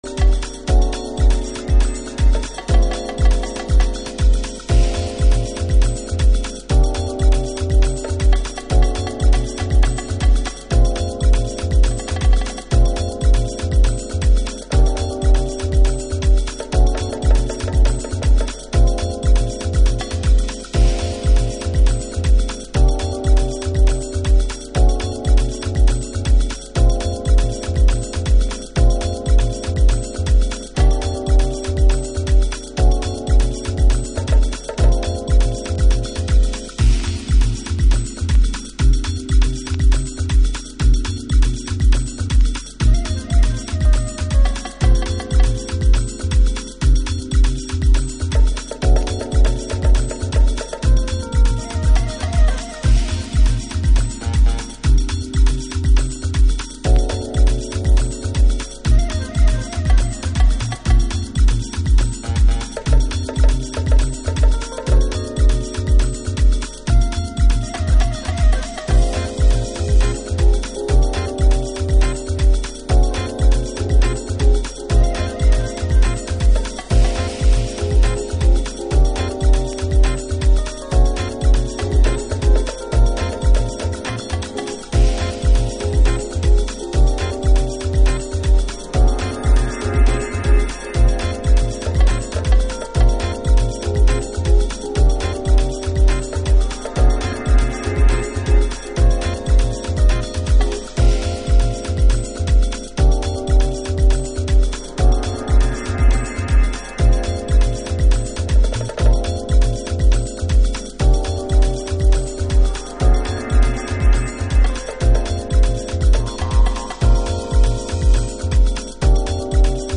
Chicago Oldschool / CDH